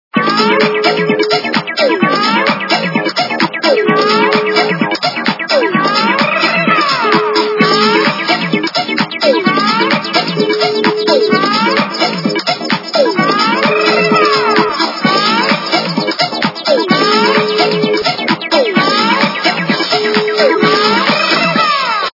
народные